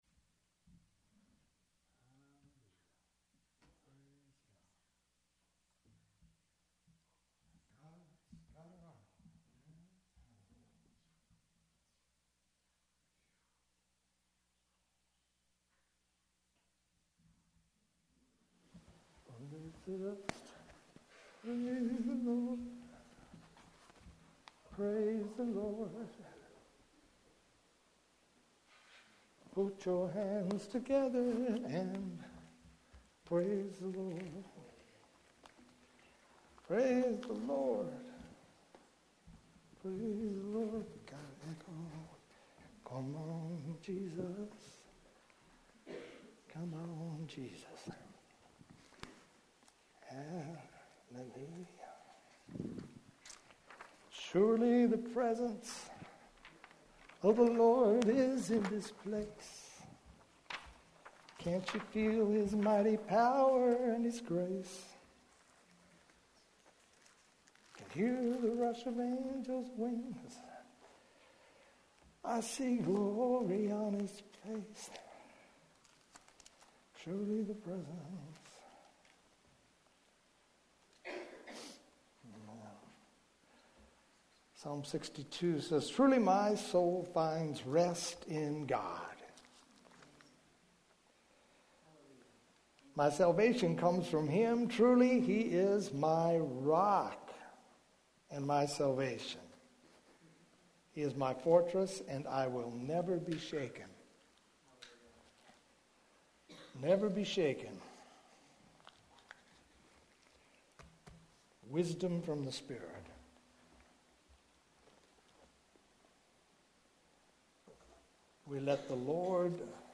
Longer sermons are broken up into smaller...